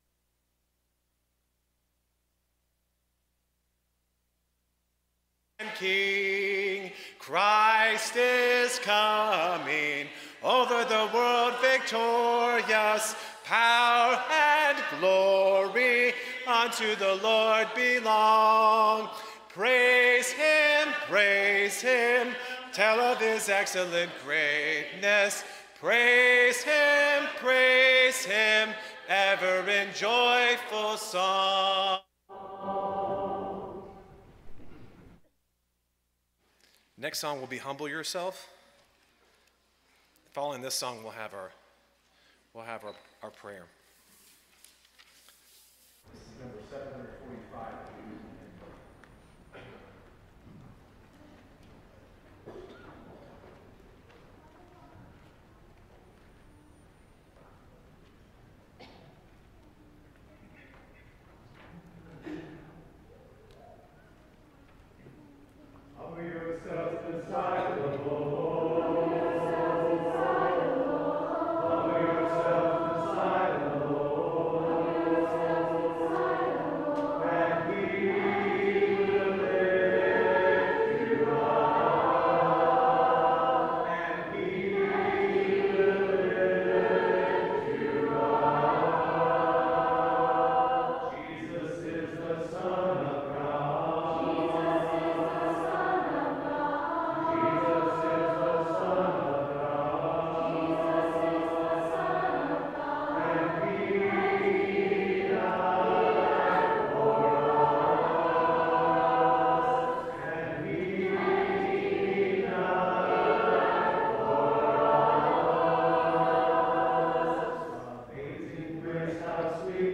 Psalm 56:8, English Standard Version Series: Sunday AM Service